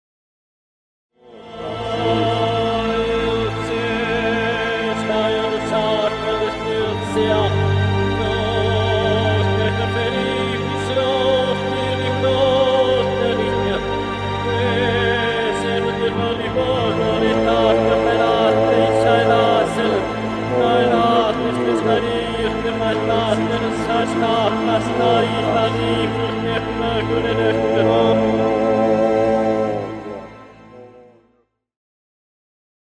Genere: musica classica
Rovesciato